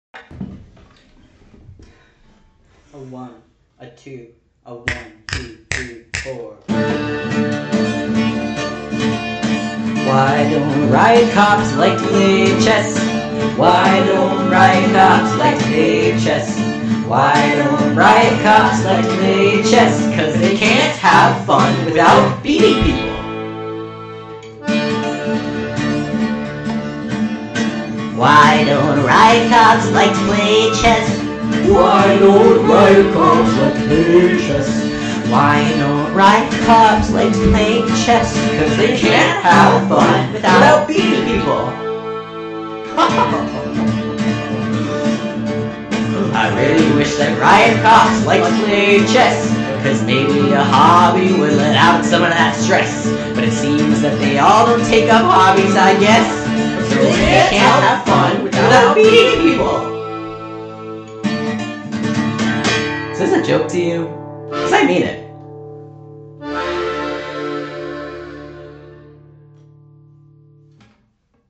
Spoiler More a 35 minute skirmish than a song.